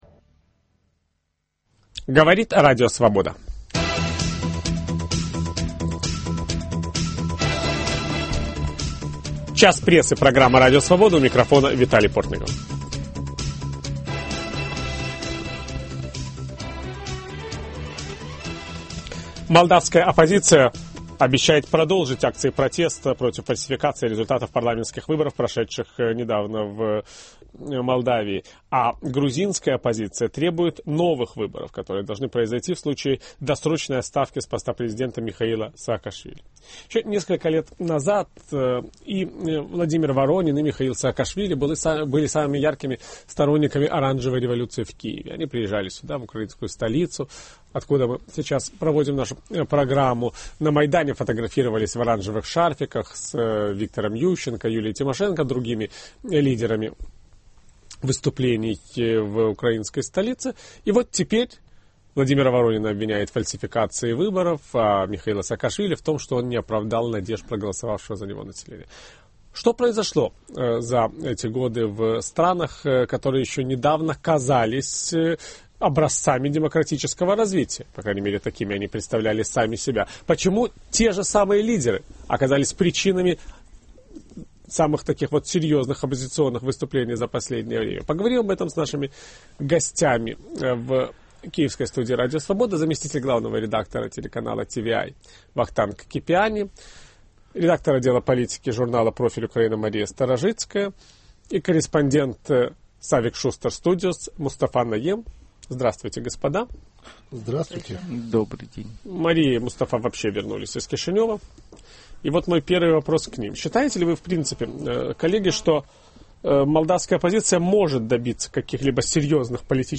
Почему оппозиция в Молдавии и Грузии продолжает борьбу с властью, еще недавно заявлявшей о собственной революционности? Виталий Портников обсуждает этот феномен с украинскими журналистами, еще не забывшими, как менялись общественные настроения после событий 2004 года.